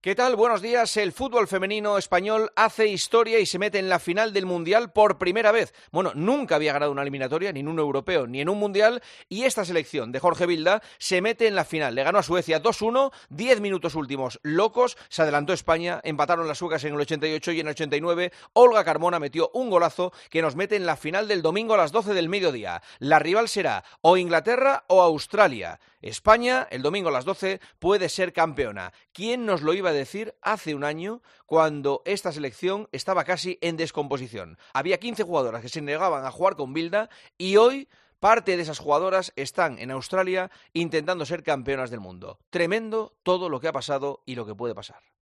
Juanma Castaño analiza en Herrera en COPE el pase de España a la final del Mundial femenino